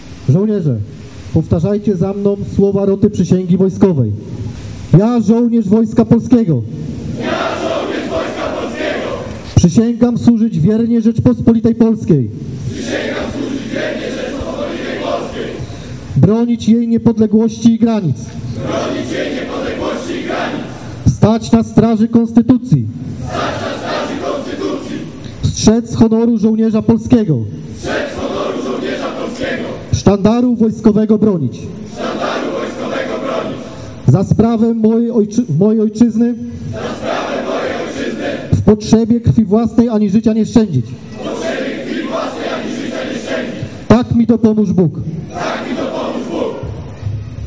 W Stargardzie odbyły się obchody 6 rocznicy powstania 14 Zachodniopomorskiej Brygady Obrony Terytorialnej, podczas której przysięgę złożyło 78 żołnierzy. Miało to miejsce na Rynku Staromiejskim.
slubowanie.mp3